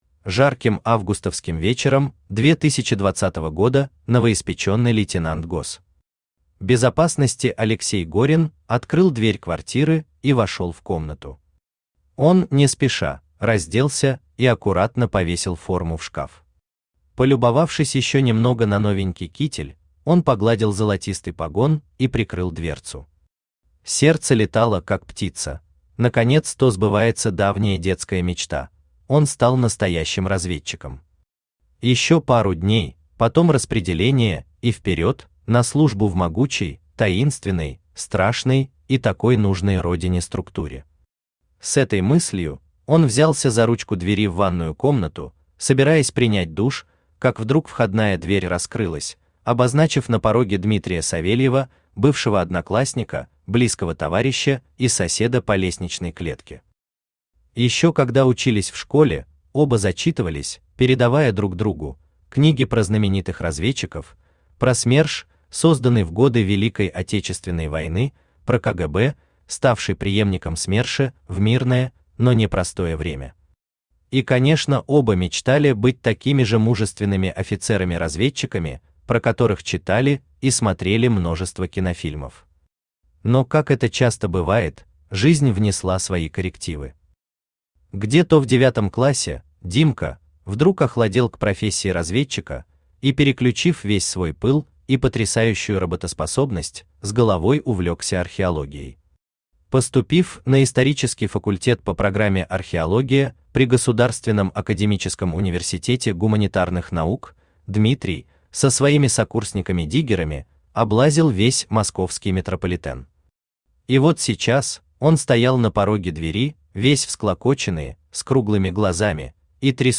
Аудиокнига Мы из… прошлого!
Автор Алексей Кузнецов Читает аудиокнигу Авточтец ЛитРес.